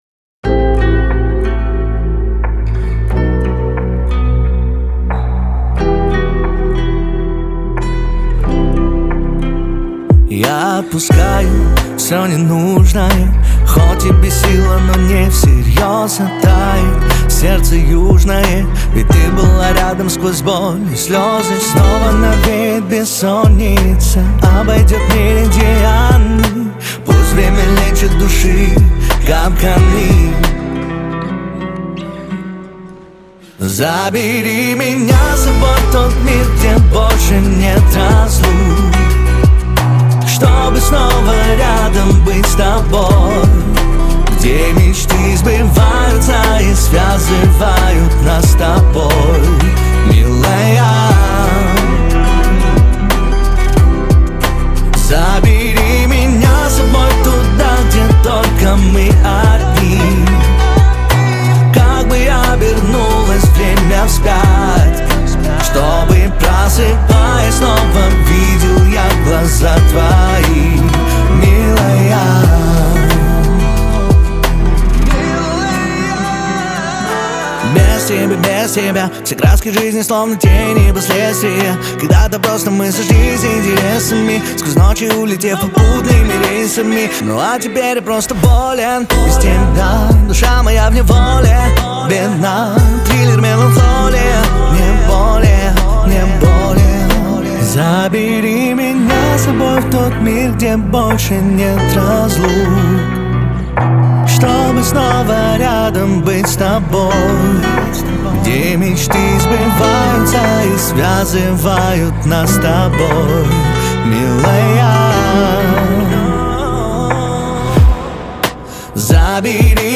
Жанр: Русские